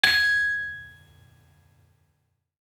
Saron-4-G#5-f.wav